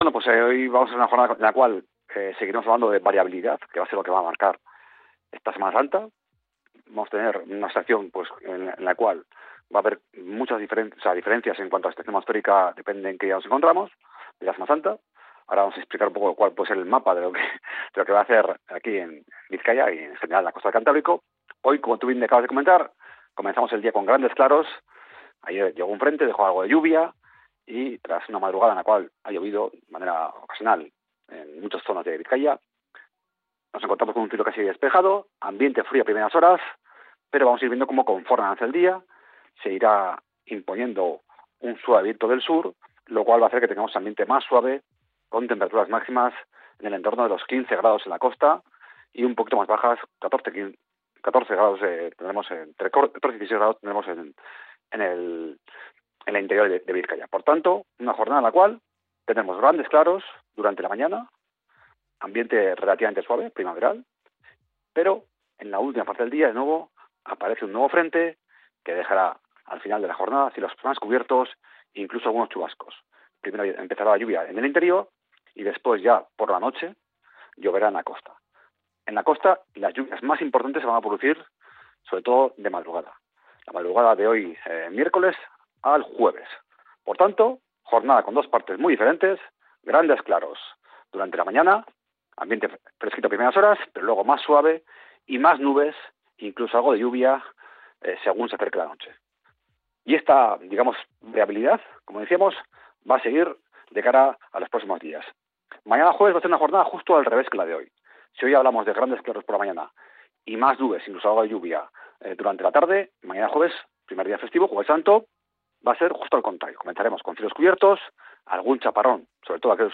El pronóstico del tiempo para este miércoles 16 de abril